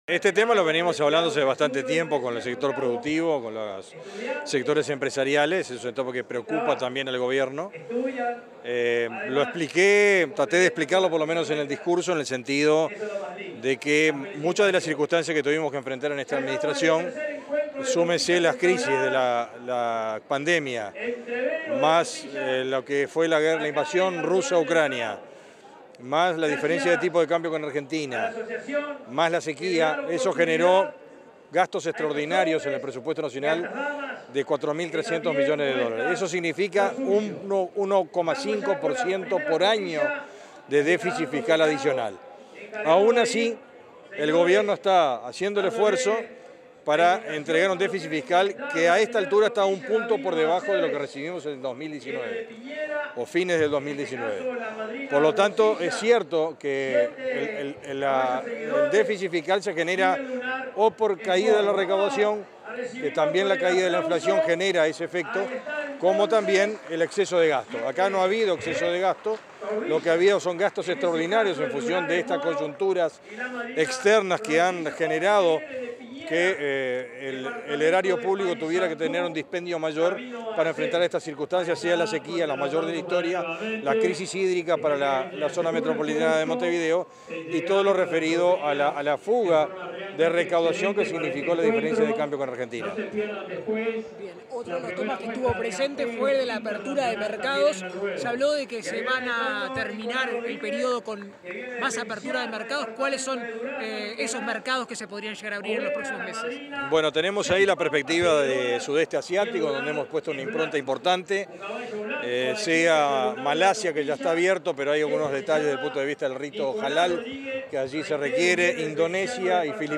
Declaraciones del ministro de Ganadería, Agricultura y Pesca, Fernando Mattos
Declaraciones del ministro de Ganadería, Agricultura y Pesca, Fernando Mattos 14/09/2024 Compartir Facebook X Copiar enlace WhatsApp LinkedIn Tras el cierre de la Expo Prado 2024, este 14 de setimbre, el ministro de Ganadería, Agricultura y Pesca, Fernando Mattos, realizó declaraciones a la prensa.
mattos prensa.mp3